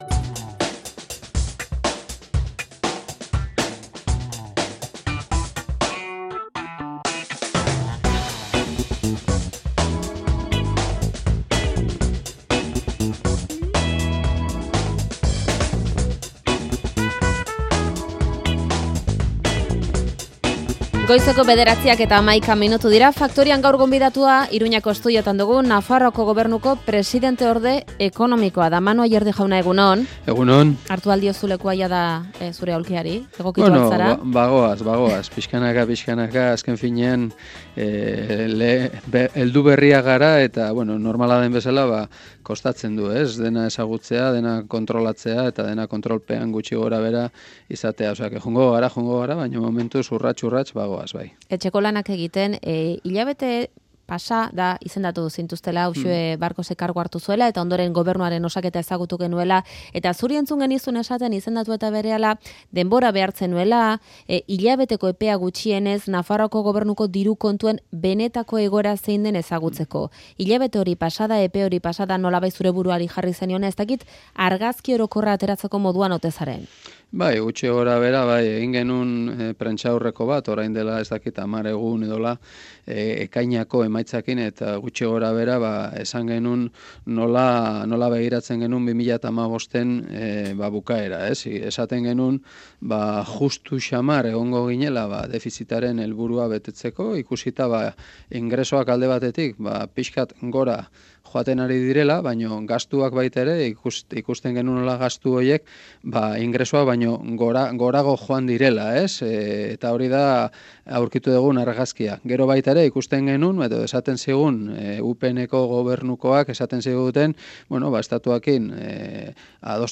Euskadi Irratiko Albiste Faktorian Manu Aierdi Nafarroako Ekonomia presidenteordeari elkarrizketa Nafarroako aurrekontuez, lehentasunez eta egungo egoeraz.